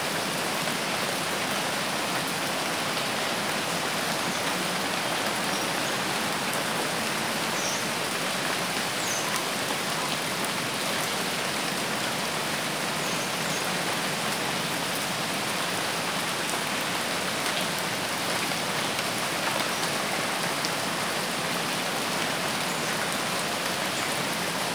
Mixkit-forest-rain-loop-1225.wav